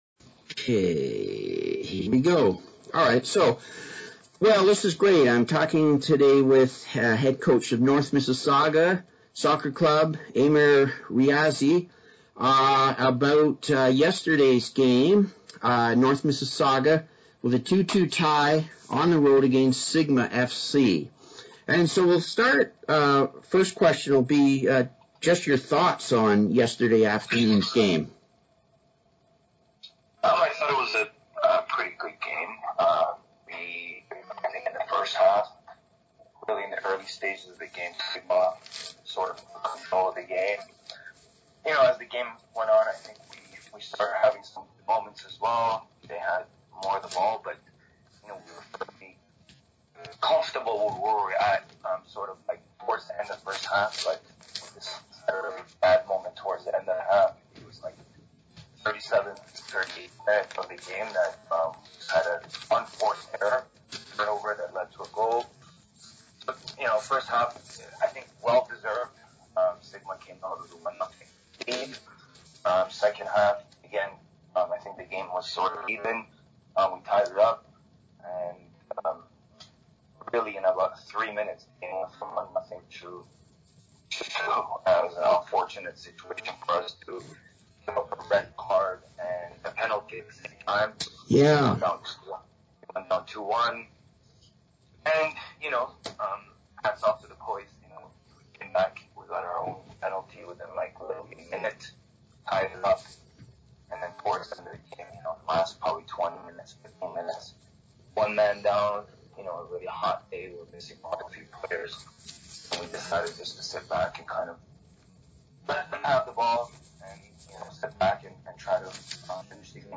Note: this interview was conducted the day after the game. Your thoughts on the game?